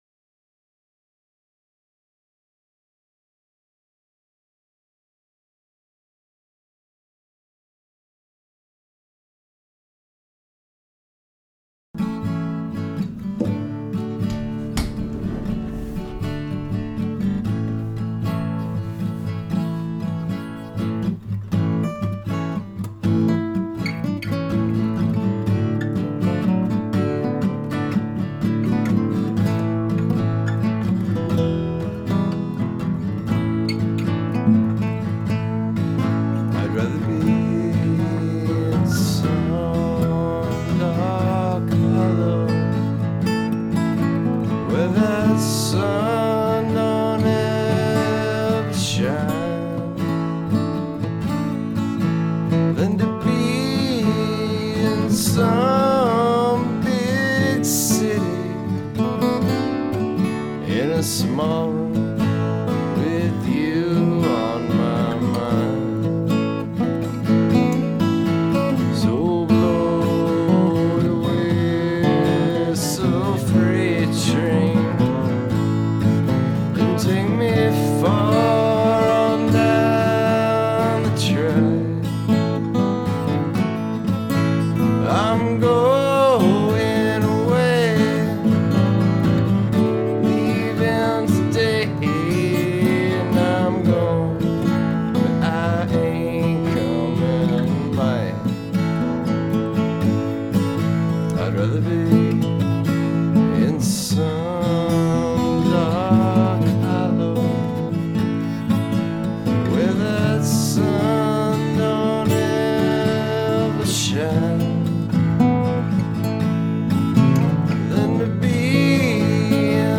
demo #1 . 2001
drums, lead guitar, bass
rhythm guitar, vocals, some lead guitar